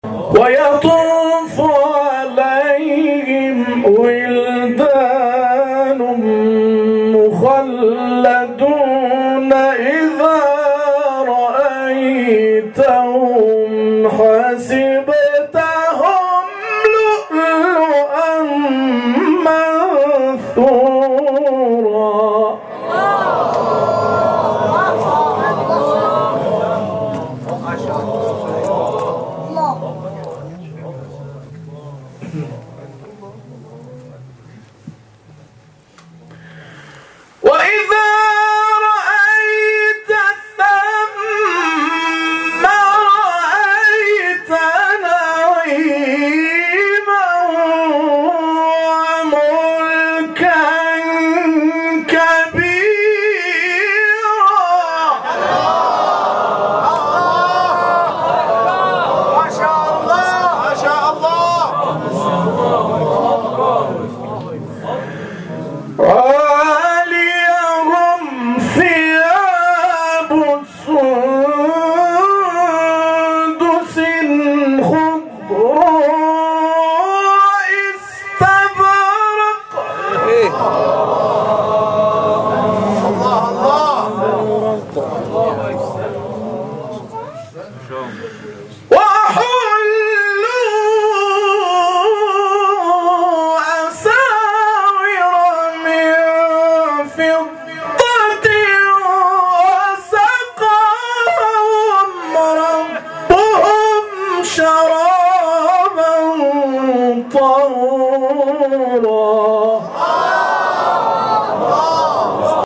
گروه شبکه اجتماعی: مقاطعی از تلاوت‌های صوتی قاریان برجسته کشور ارائه می‌شود.